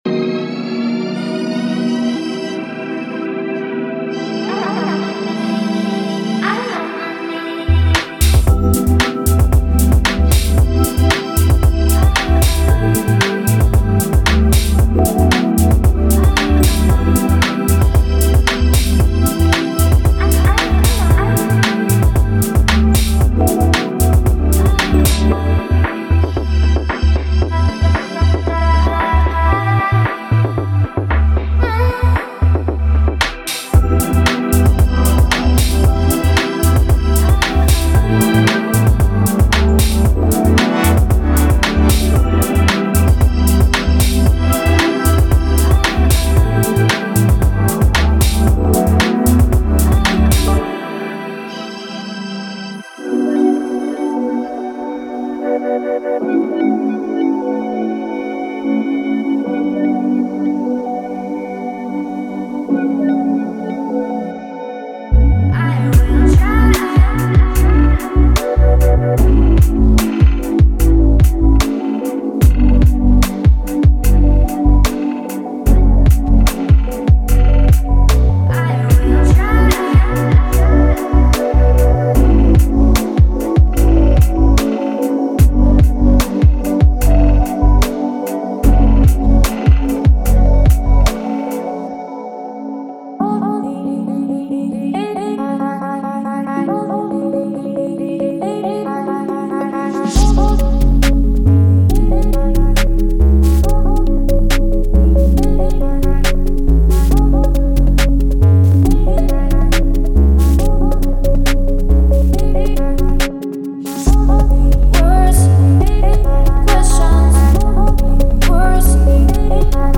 テンポは74〜138 BPMに対応し、ミッドテンポの楽曲からアップビートなポップリズムまで幅広くカバーします。
デモサウンドはコチラ↓
Genre:Future Pop
74 - 138 BPM